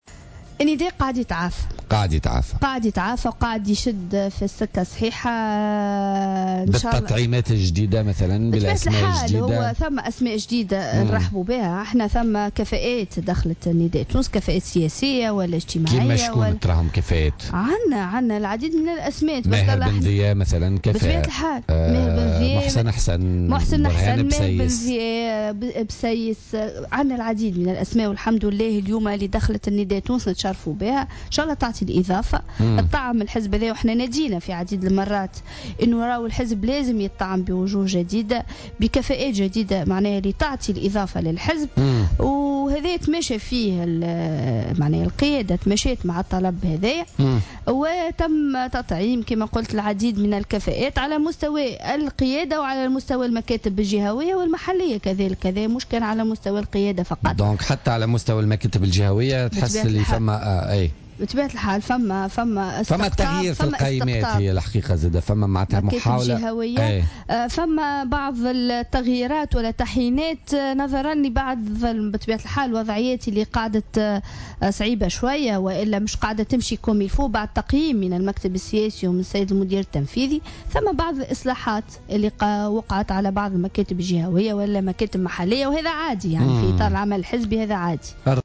وأضافت خلال مداخلة لها اليوم في برنامج "بوليتيكا" أنه تم تطعيم الحزب بوجوه جديدة والعديد من الكفاءات على مستوى القيادة والمكاتب الجهوية أيضا.